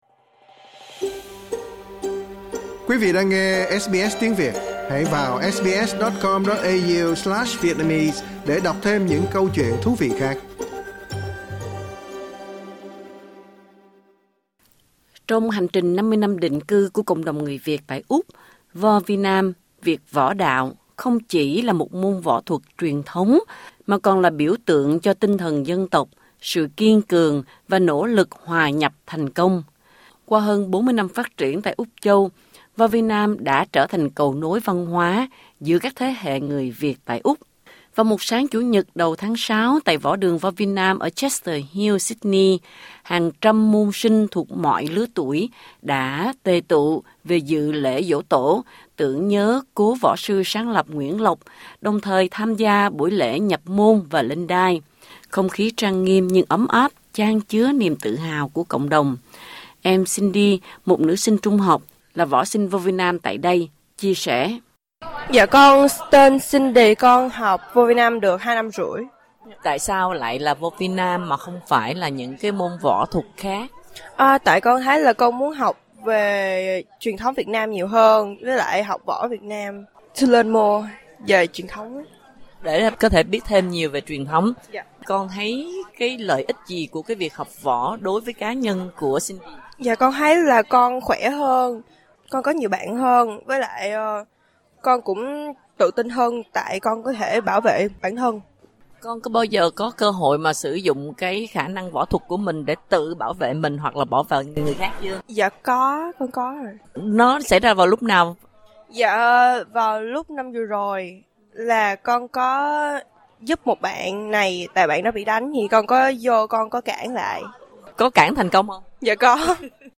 Việt Võ Đạo không chỉ đơn thuần là một môn võ truyền thống mà còn là biểu tượng văn hóa gắn kết cộng đồng người Việt xa quê. Ngày lễ Giỗ Tổ Vovinam tại võ đường Chester Hill Sydney, các môn sinh và võ sư chia sẻ các câu chuyện, làm nổi bật vai trò của Vovinam trong việc rèn luyện thể chất, nuôi dưỡng đạo đức, và giữ gìn bản sắc Việt trong cộng đồng người Việt tại Úc.